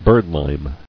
[bird·lime]